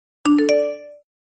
• Качество: высокое
Звук уведомления в WhatsApp - Вы слышите новое сообщение?